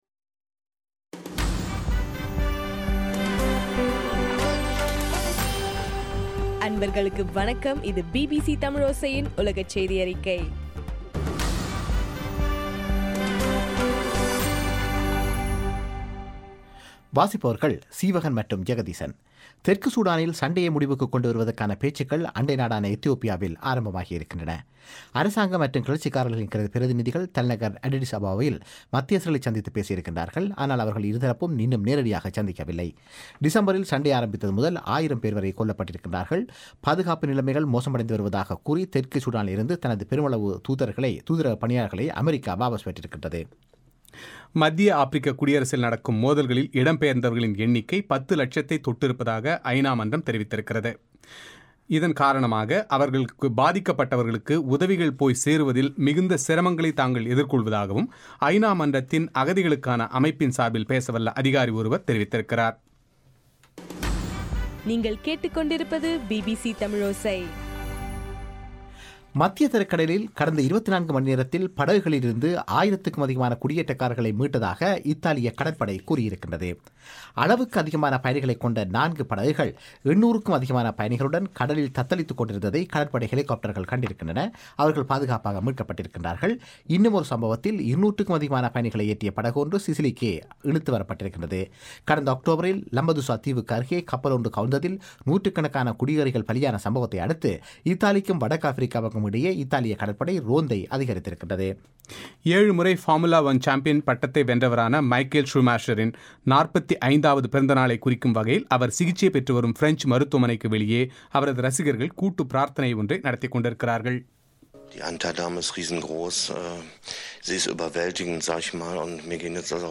பிபிசியின் உலகச் செய்தியறிக்கை ஜனவரி 3